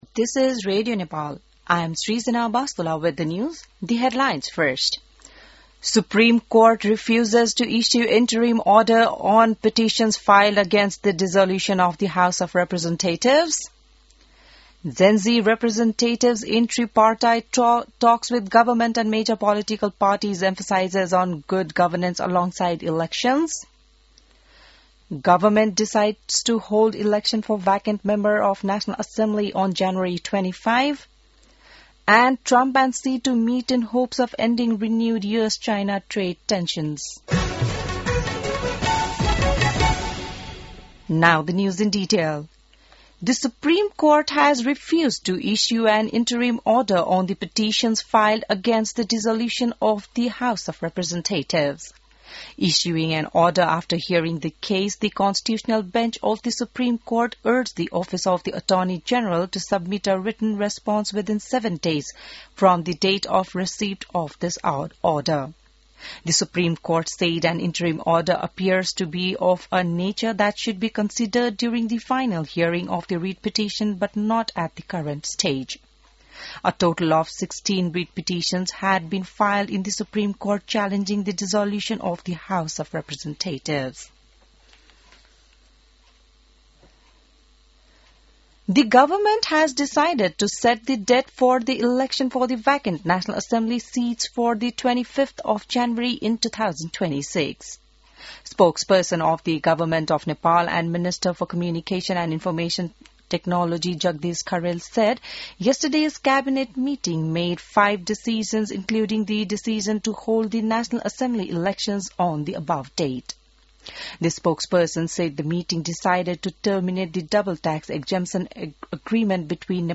बिहान ८ बजेको अङ्ग्रेजी समाचार : १३ कार्तिक , २०८२